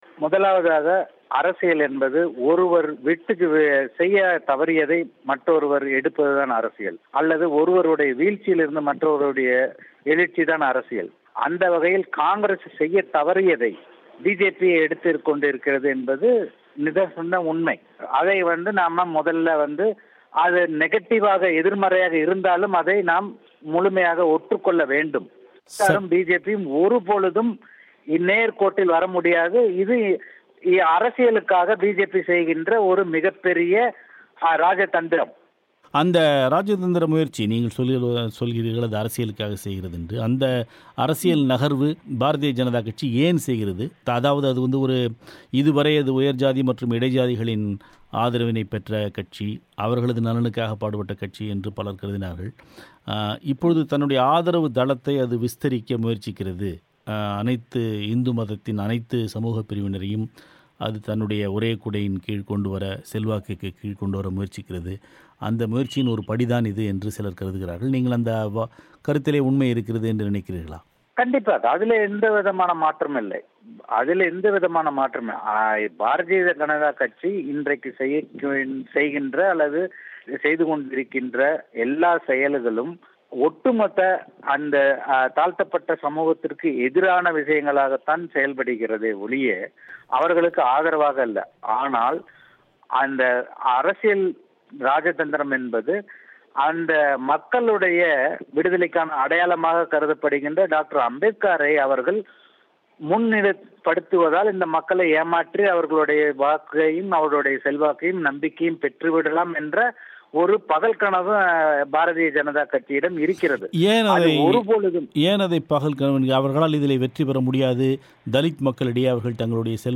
பேட்டி.